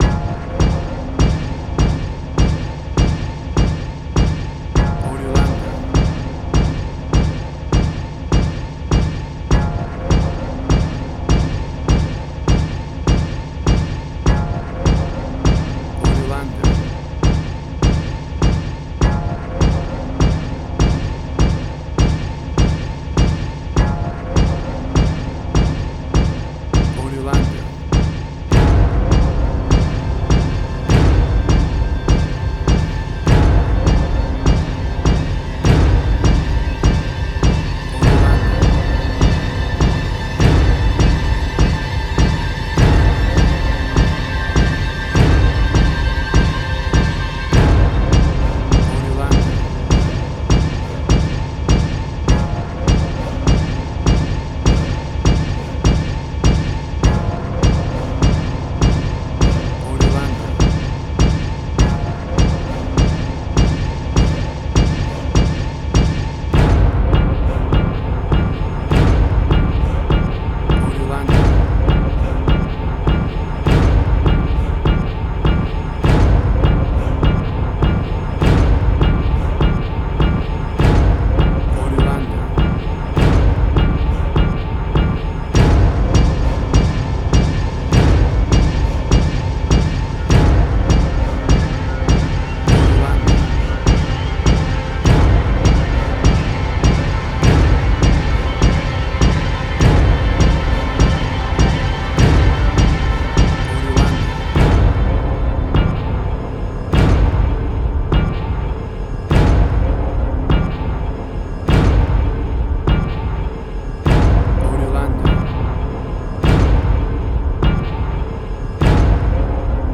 Post-Electronic.
WAV Sample Rate: 16-Bit stereo, 44.1 kHz
Tempo (BPM): 101